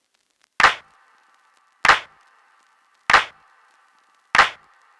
Lucky Clap Loop.wav